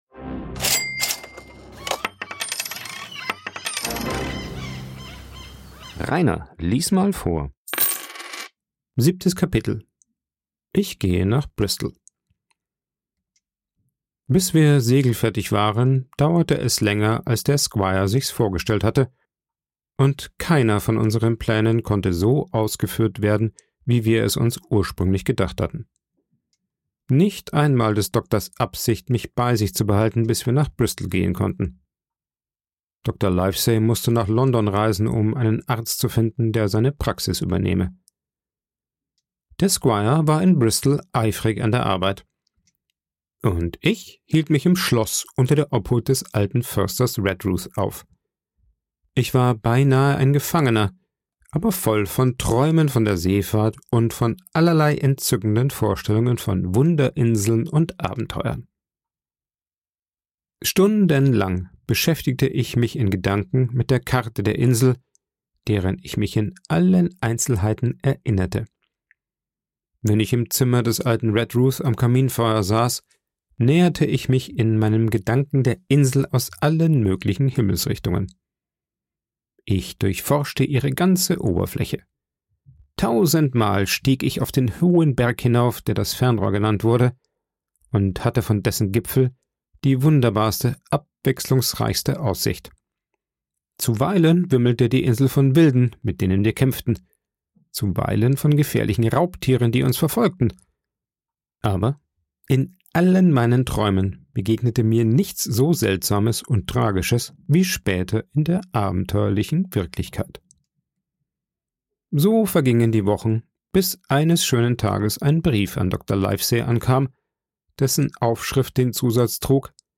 aufgenommen und bearbeitet im Coworking Space Rayaworx, Santanyí, Mallorca.